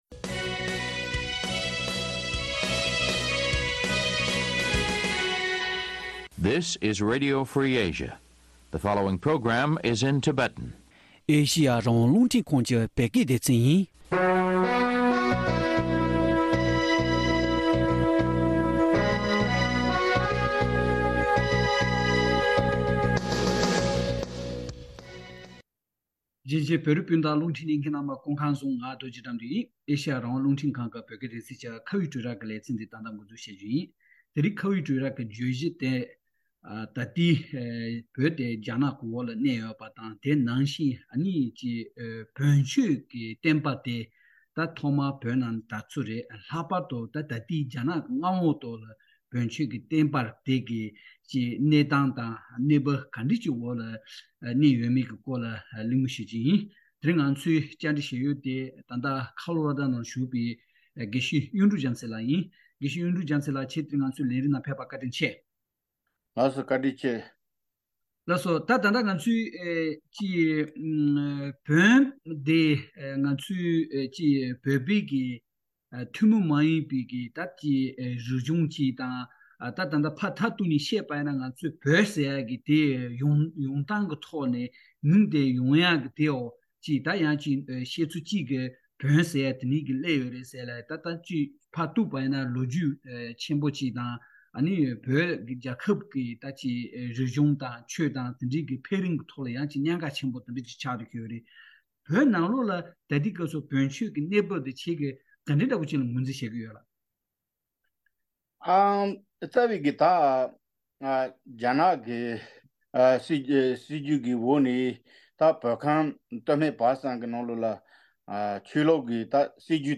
བོན་ཀྱི་ཆོས་ལུགས་བྱུང་སྟངས་དང་བོད་ནང་རྒྱ་ནག་གིས་བཙན་འཛུལ་བྱས་རྗེས་ཉམ་རྒུད་ཕྱིན་པ་དེ་བཞིན་སླར་གསོ་གང་འདྲ་བྱུང་ཡོད་མེད་སྐོར་གླེང་མོལ།